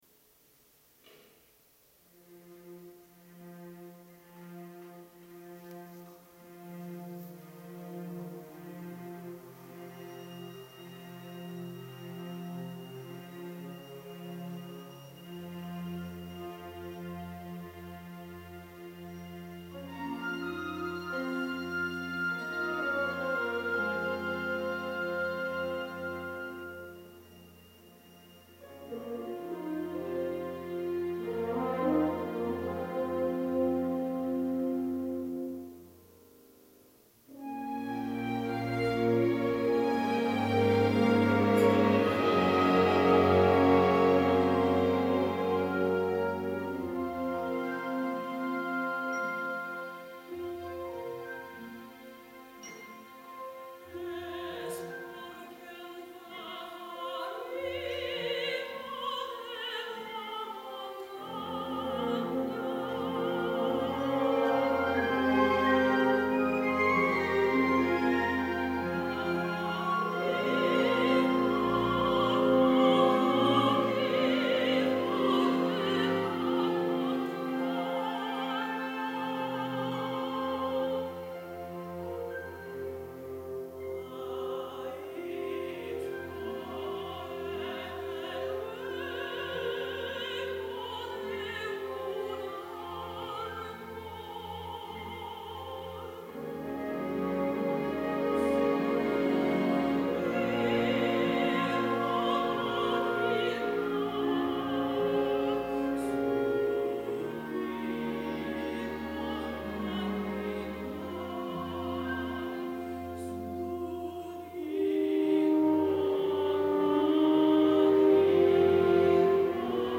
Symphonic Song Cycle